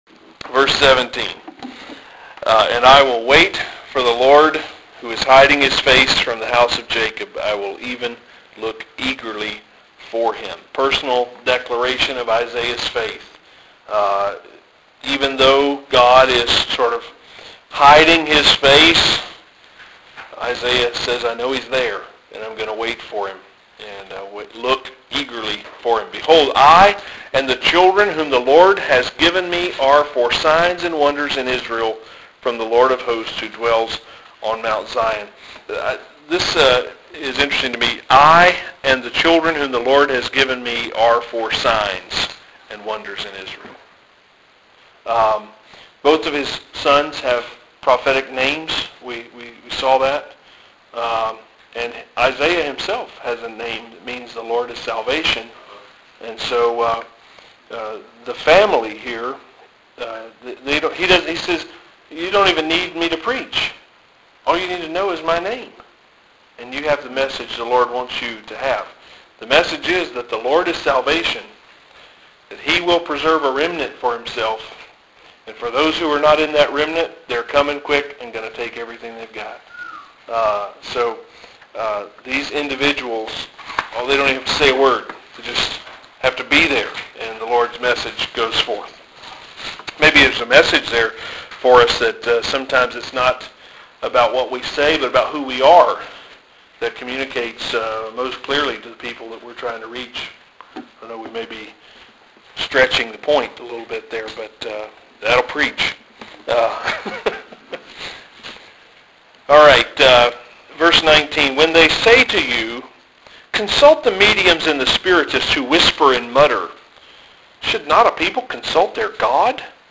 Seminary Extension Audio from lectures on Isaiah 9-12 is available here.